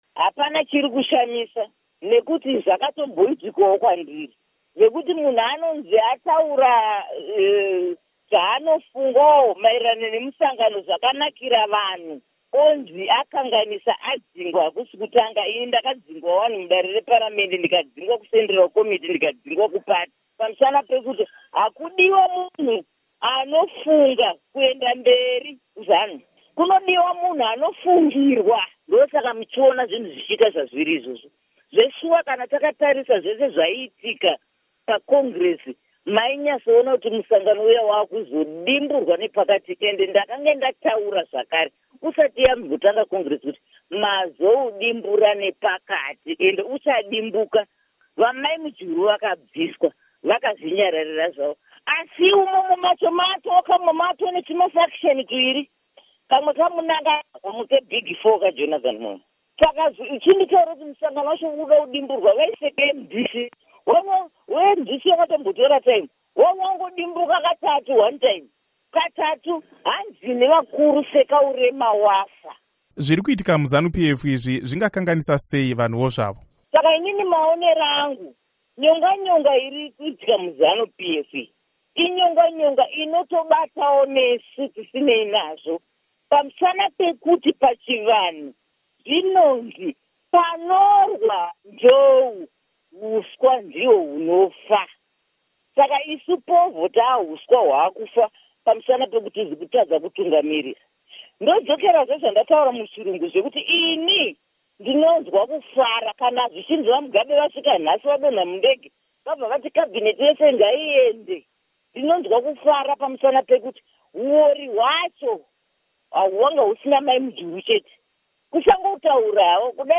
Hurukuro naAmai Margaret Dongo